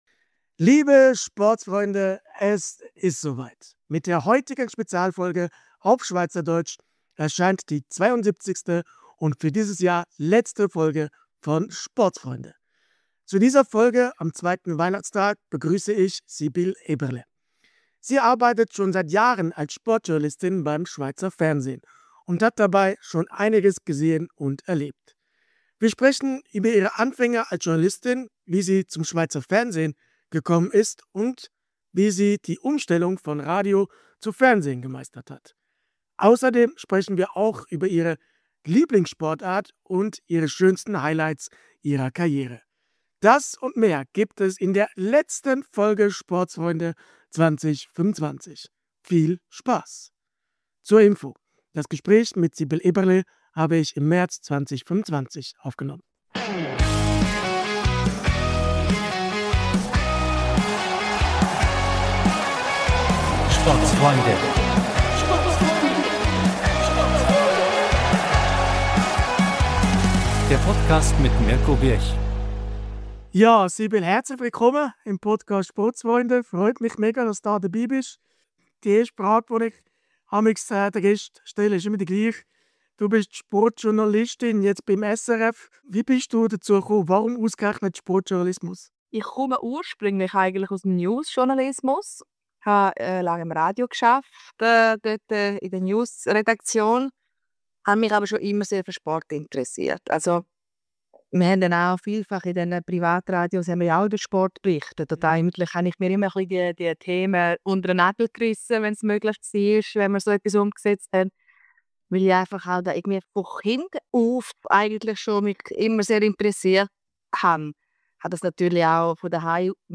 Mit der heutigen SPEZIALFOLGE auf SCHWEIZERDEUTSCH erscheint die 72. und somit letzte Folge von SPORTSFREUNDE im Jahr 2025!